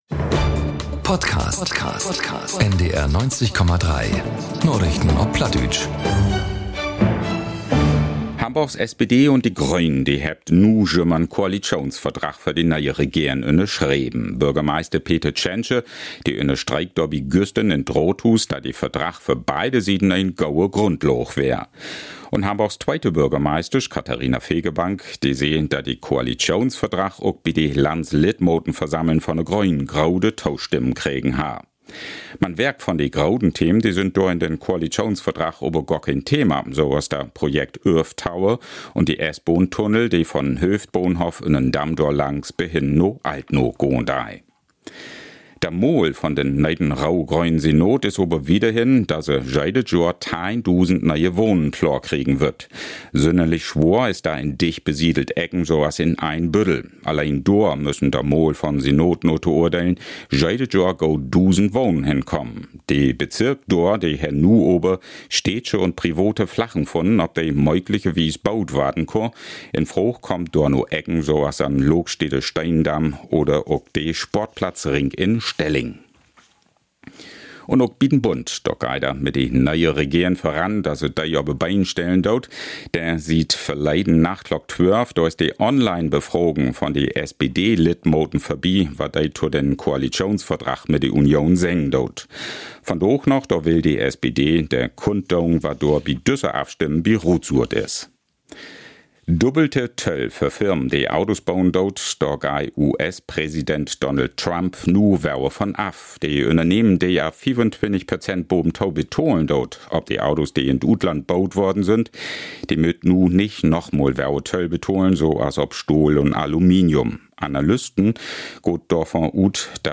… continue reading 264 episode # Narichten Op Platt # NDR 90,3 # NDR 90 # News Talk # Nachrichten # Tägliche Nachrichten